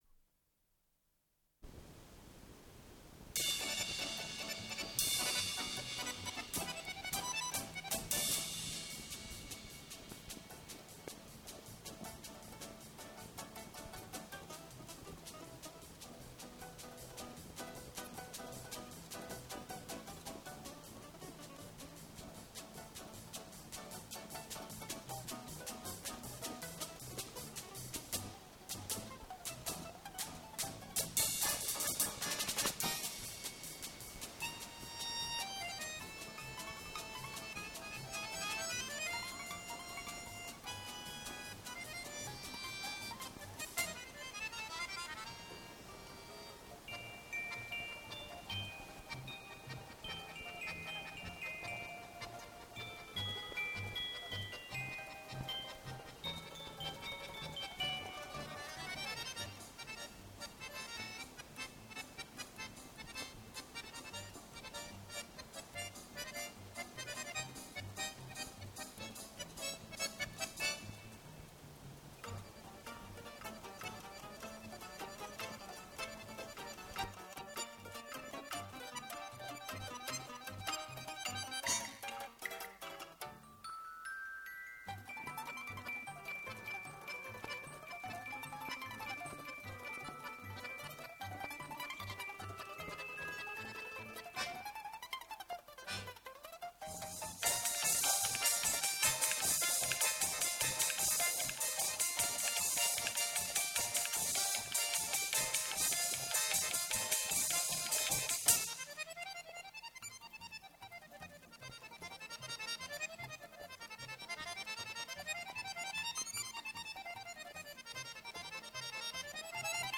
русский  инструментальный ансамбль
балалайка
баян
ударные.
Запись 1983 год Дубль моно